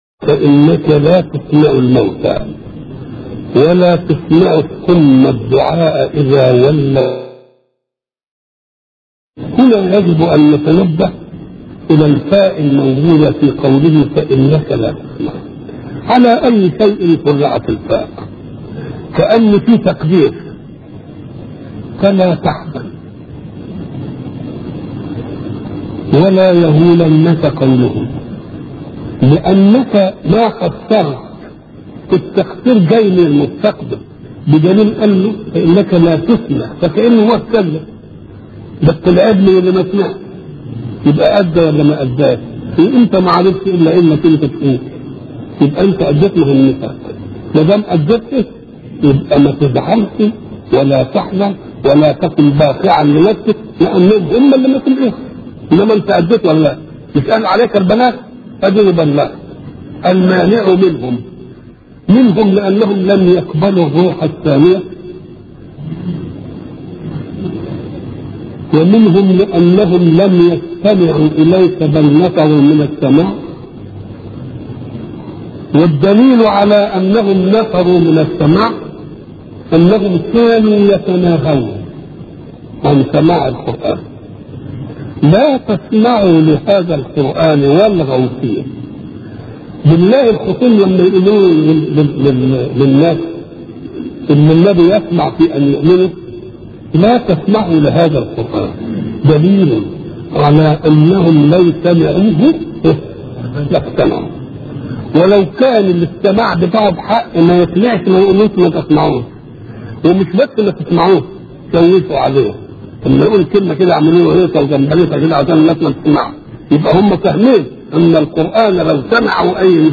أرشيف الإسلام - أرشيف صوتي لدروس وخطب ومحاضرات الشيخ محمد متولي الشعراوي